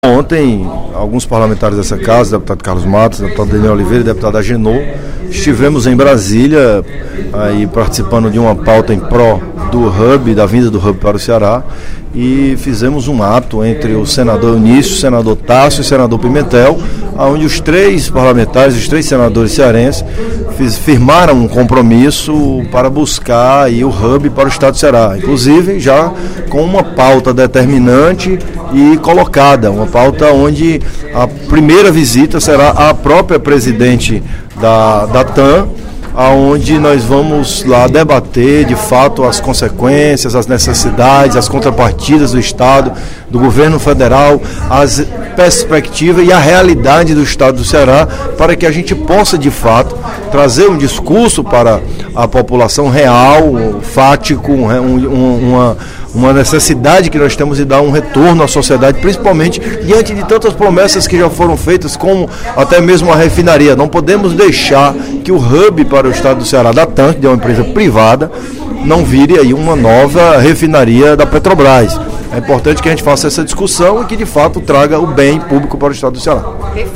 O deputado Danniel Oliveira (PMDB) informou, durante o primeiro expediente da sessão plenária desta quarta-feira (17/06), que uma comitiva de três deputados cearenses – formada por Carlos Matos (PSDB), Agenor Neto (PMDB) e ele  - foi a Brasília, na última terça-feira (16/06), para uma reunião com os senadores cearenses José Pimentel (PT), Eunício Oliveira (PMDB) e Tasso Jereissati (PSDB). Na ocasião, foi firmado um termo de compromisso em defesa da instalação do Hub da Latam Airlines - formada pela chilena LAN e pela brasileira TAM - em Fortaleza.